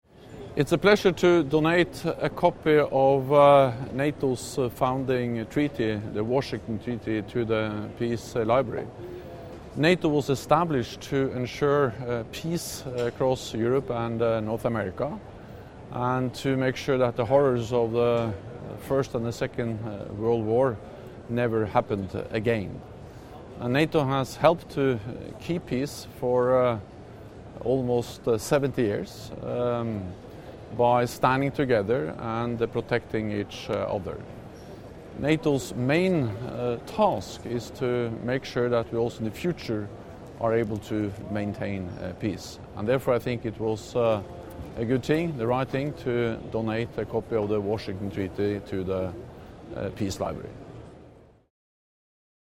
NATO Secretary General Jens Stoltenberg is taking part in a ceremony of remembrance in Paris on Sunday (11 November 2018) for the 100th anniversary of the end of the First World War. Mr. Stoltenberg joined other world leaders at the Arc de Triomphe to mark the exact moment that the guns fell silent, ending four terrible years of conflict.